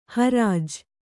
♪ harāj